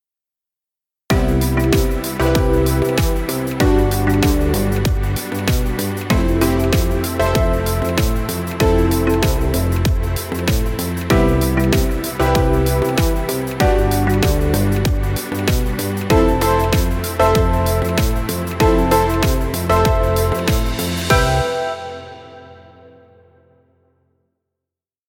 Hip-Hop – light positive music track.
Hip-Hop music for video.
Background Music.